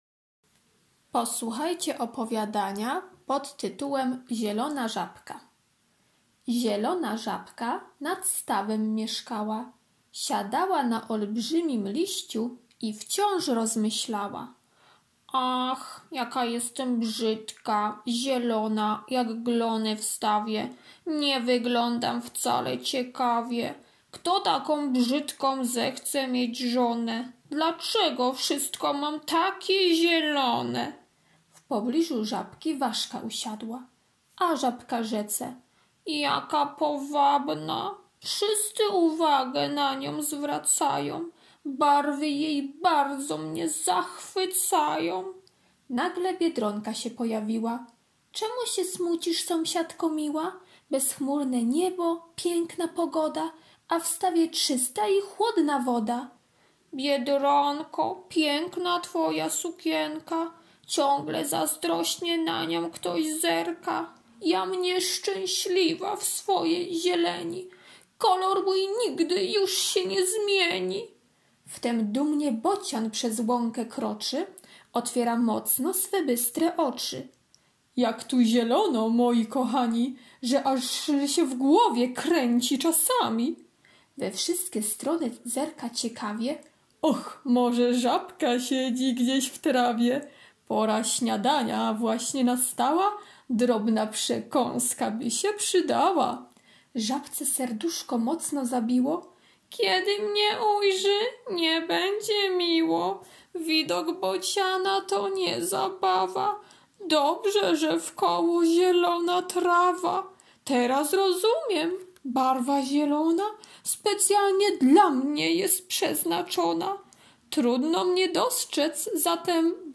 wtorek - opowiadanie "Zielona żabka" [3.76 MB] wtorek - prezentacja "Żaba" [1.43 MB] wtorek - propozycje pracy plastycznej (bez drukowania) [217.50 kB] wtorek - karta pracy nr 1 [225.17 kB] wtorek - ćw. dla chętnych - kolorowanka wg kodu [164.00 kB] wtorek - ćw. dla chętnych "Żaba" [607.33 kB] wtorek - ćw. dla chętnych "Żaby" [71.48 kB]